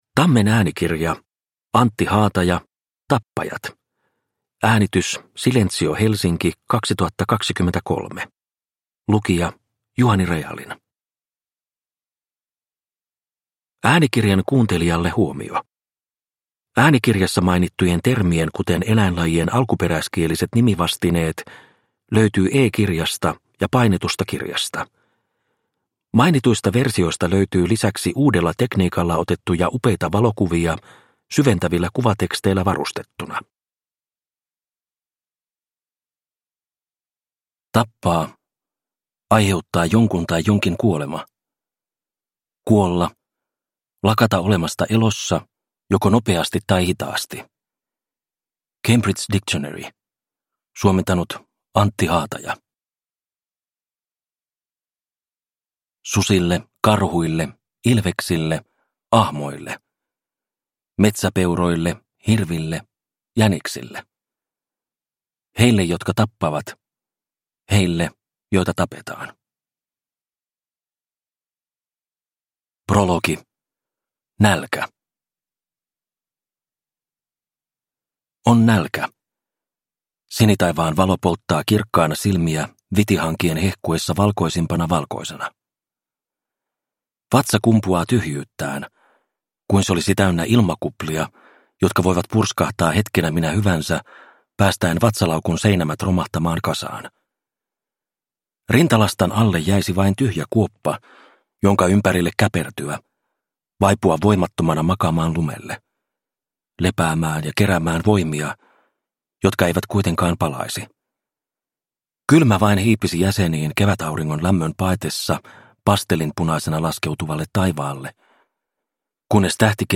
Tappajat – Ljudbok – Laddas ner